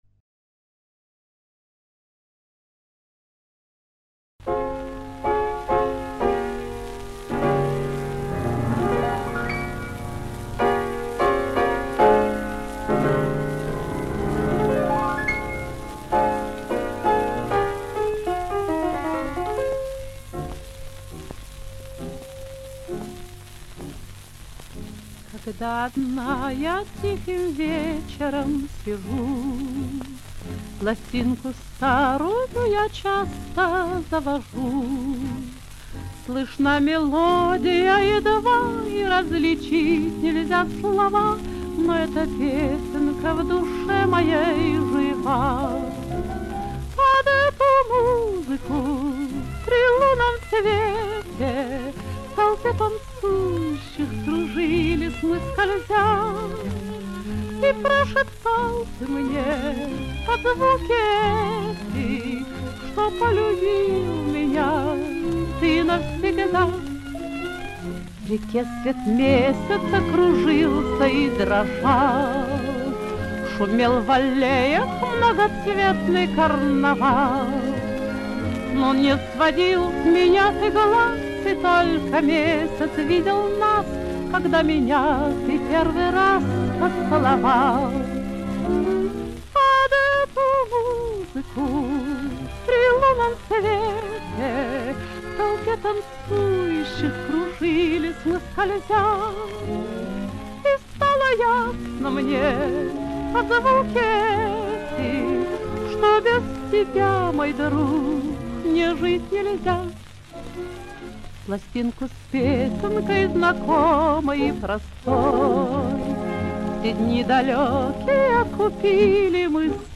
Каталожная категория: Меццо-сопрано с оркестром |
Жанр: Песня
Место записи:    Ленинград |